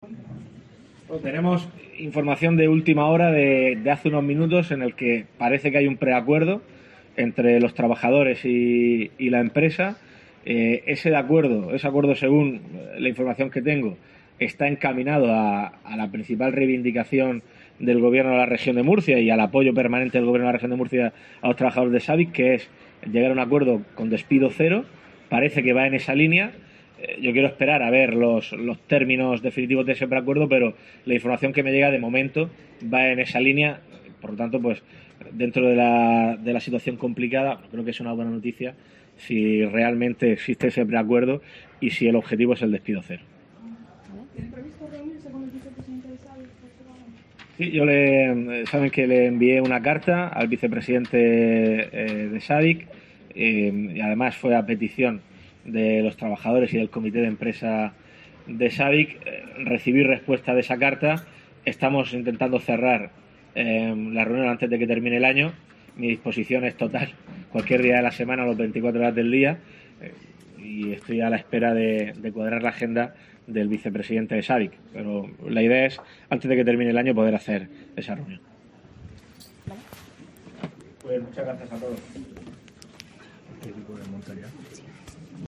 Fernando López Miras, presidente de la Región de Murcia
En un contacto con los medios de comunicación y al ser preguntado por las negociaciones entre la empresa y los trabajadores, López Miras ha destacado que hay una información de "última hora" que apunta a que parece que se ha alcanzado ese preacuerdo.